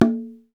PRC XBONGO0I.wav